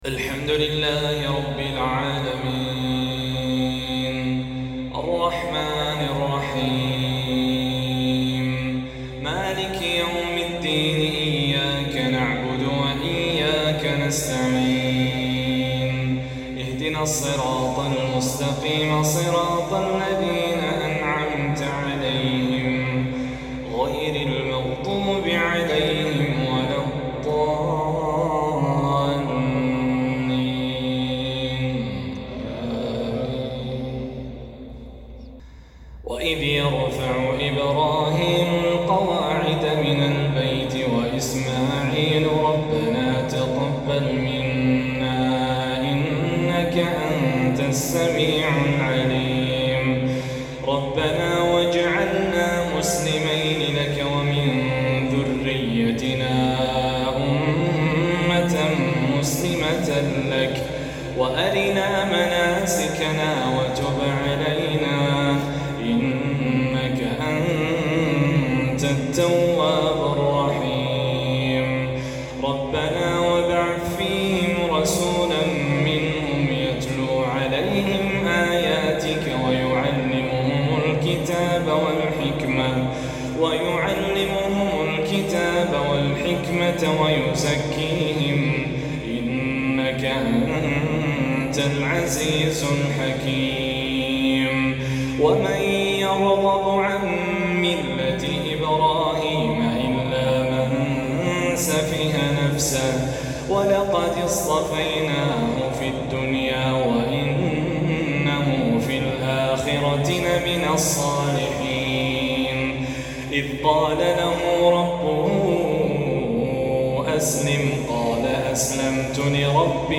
فجرية رمضانية